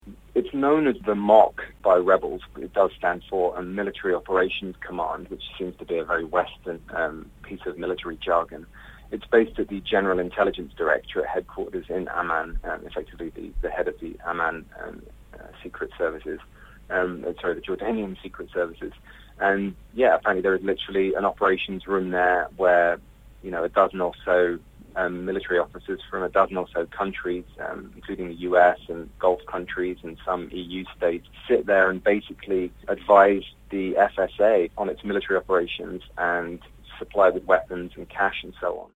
reports from Lebanon.